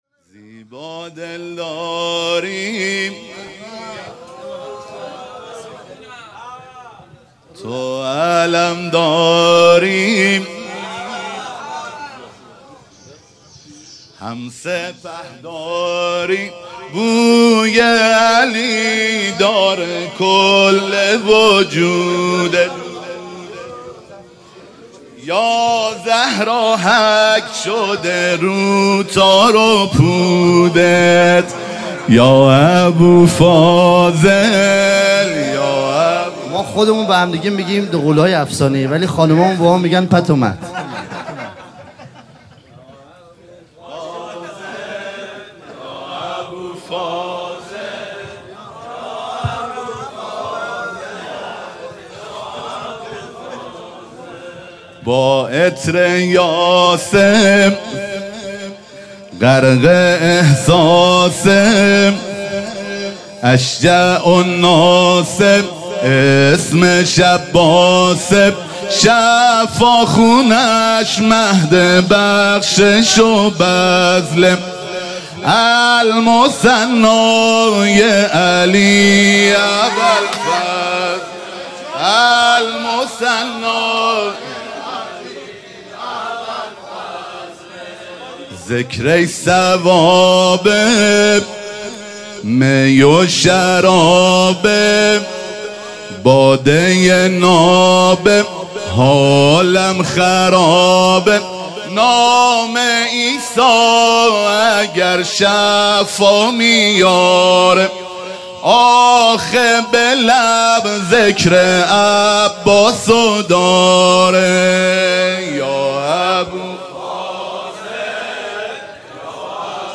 عنوان ولادت سرداران کربلا – شب دوم
سرود